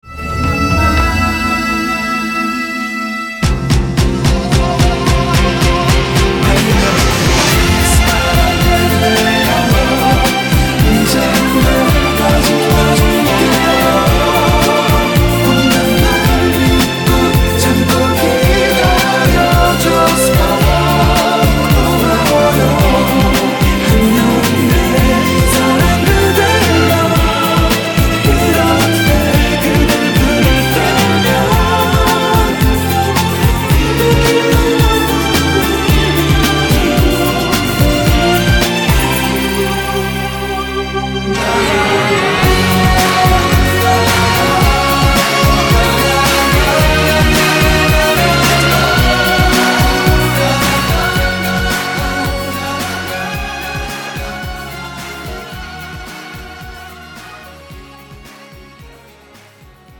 음정 코러스
장르 축가 구분 Pro MR